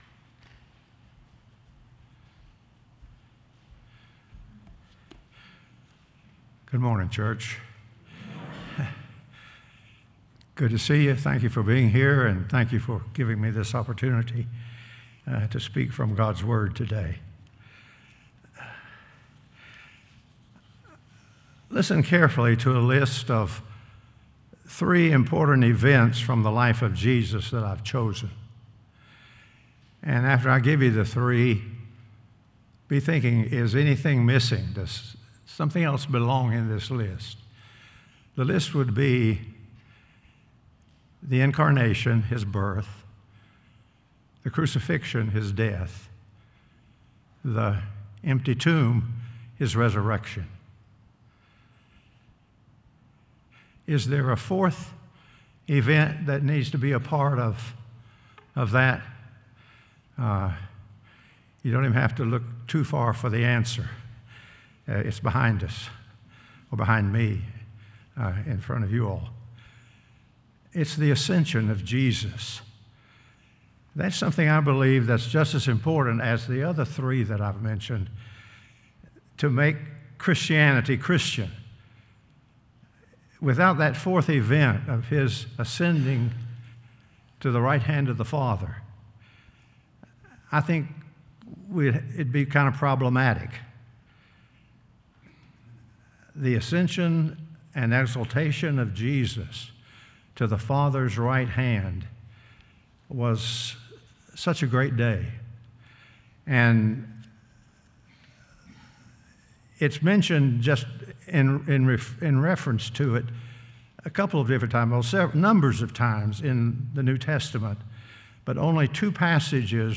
Sermons | Westport Road Church of Christ